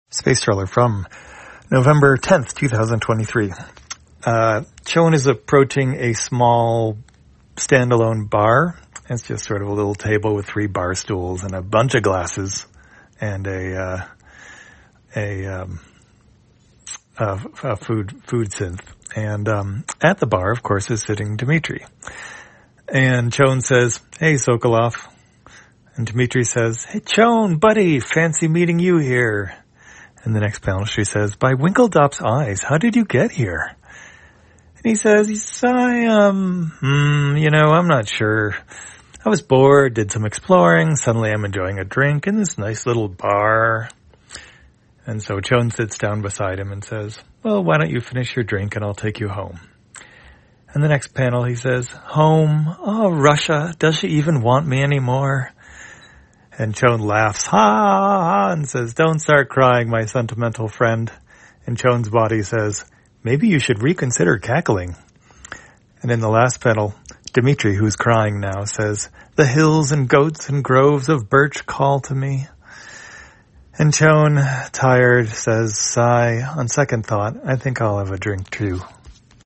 Spacetrawler, audio version For the blind or visually impaired, November 10, 2023.